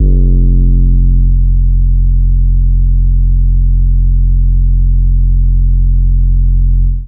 808 (Sanctuary)_1 - Loop_2.wav